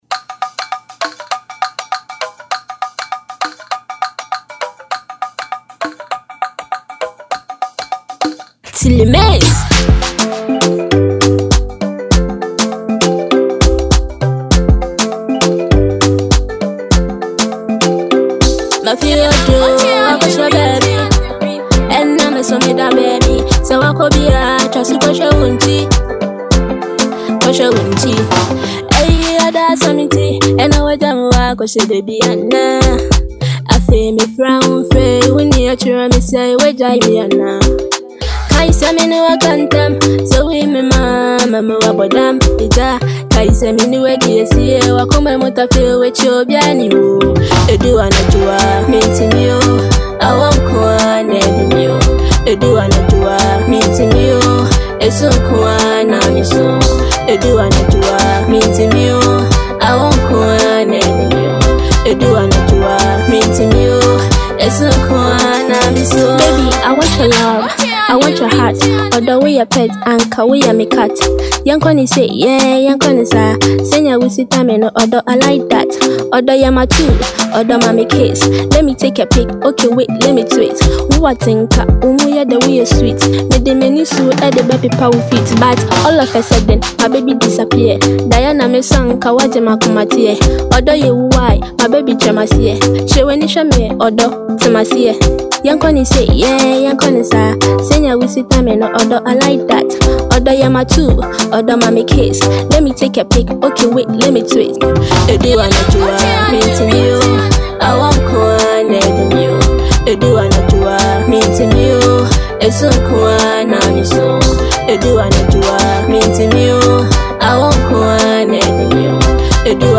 Afrobeats
a great love song every lover should enjoy.